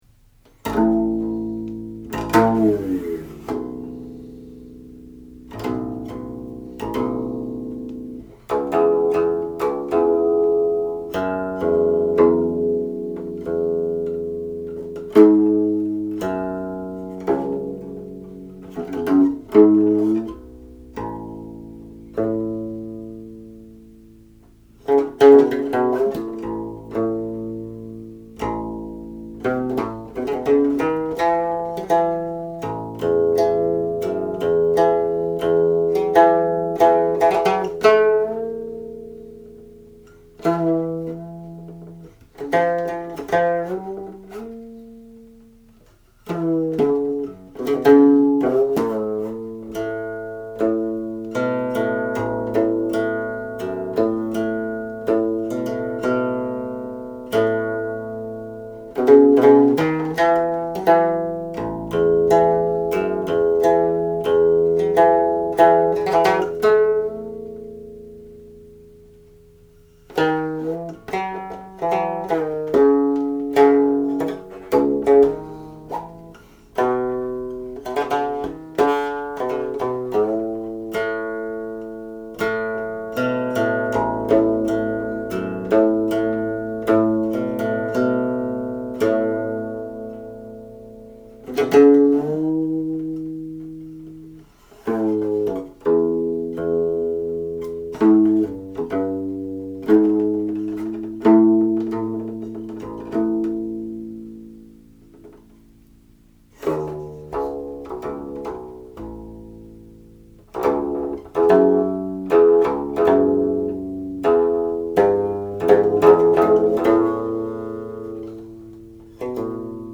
In my recording of that option I used xiewo as such an ornament: it seems to heighten the resulting opening octave in a way that accentuates the the mountain theme already discussed here.
Opening with "八上半寸許 a little above 8" (in this case 7.6) yields a perfect octave, the same notes that end the piece.